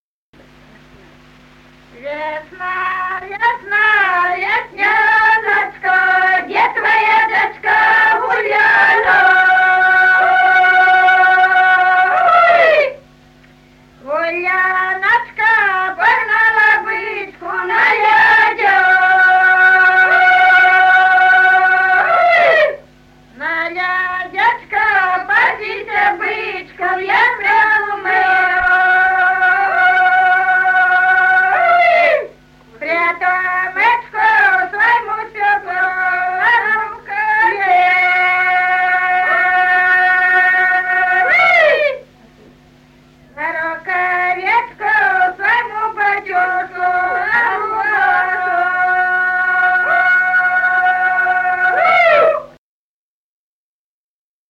с. Курковичи.